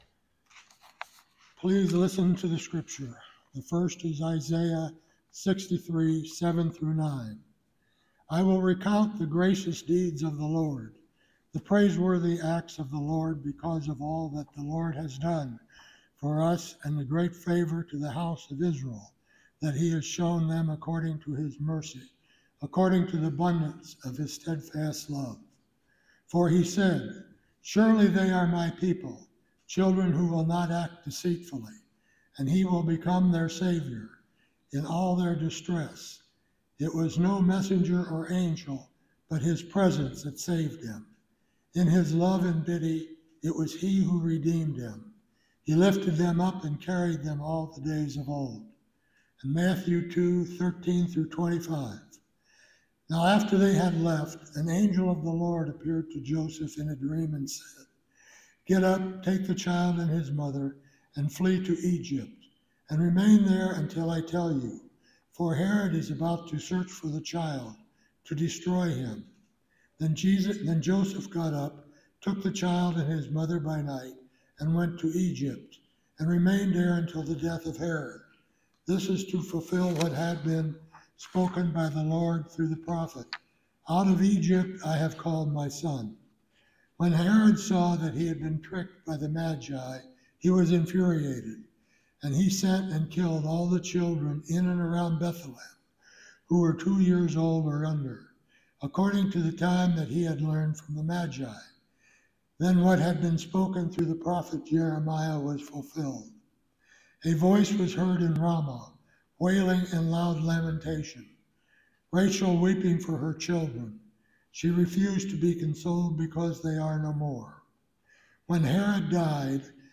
Service Type: Sunday Worship